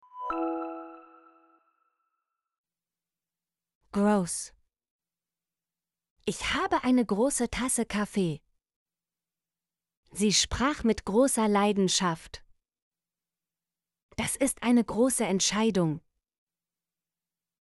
große - Example Sentences & Pronunciation, German Frequency List